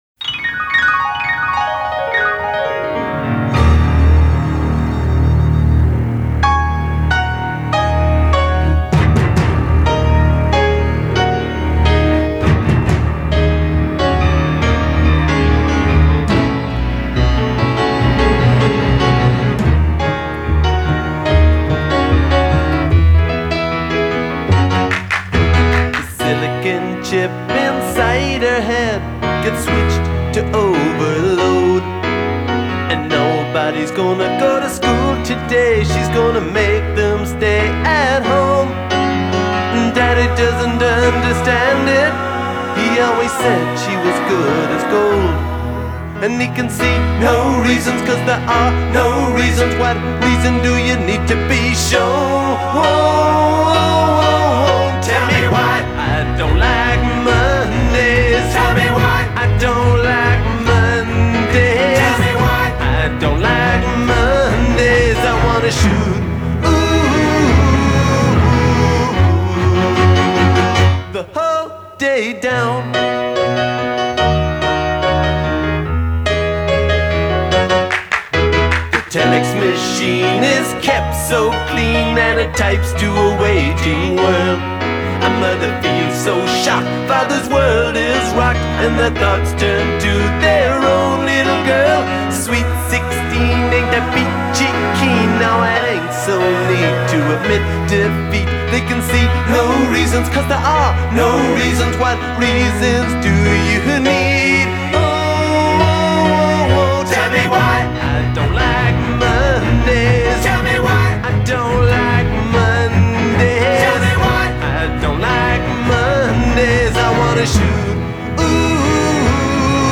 the lead singer of the Irish punk rock band